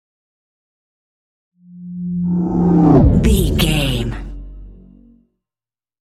Whoosh deep large
Sound Effects
Atonal
dark
tension